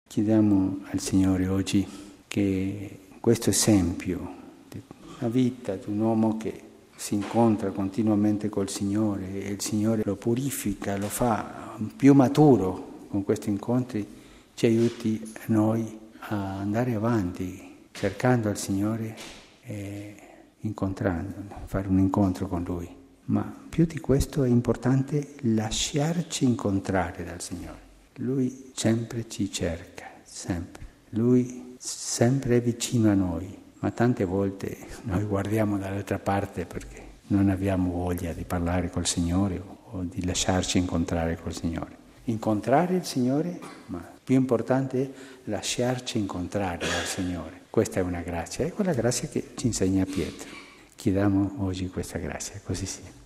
Det sa påven sammanfattningsvis i fredagsmorgonens mässa i Santa Martakapellet, där några anställda vid Vatikanmuséerna deltog.